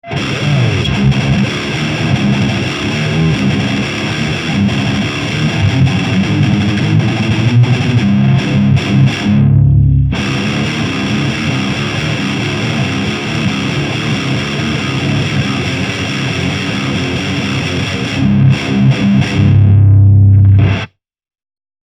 サンプルはブースターとしての違い程度です。
JCM2000 DSL100
GAIN7 Bass8 Middle8 Treble7
LEED2 BEHRINGER PREAMP BOOSTER